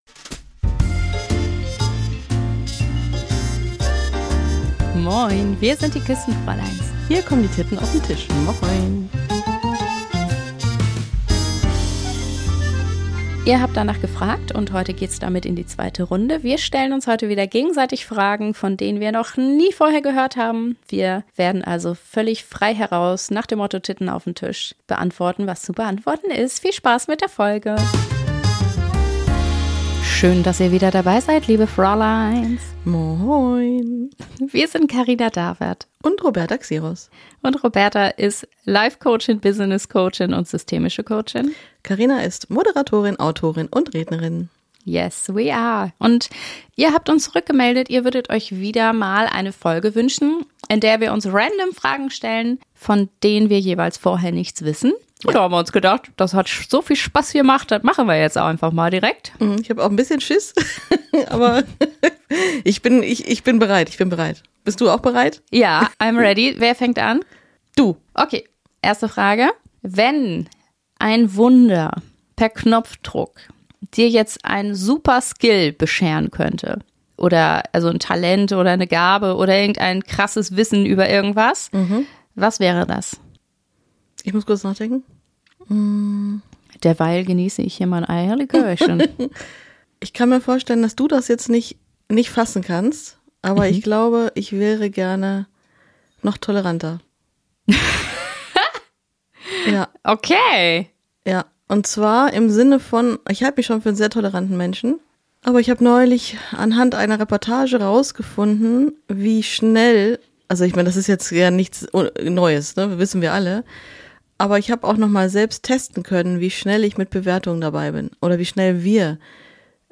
Live und ungefiltert hier im Podcast.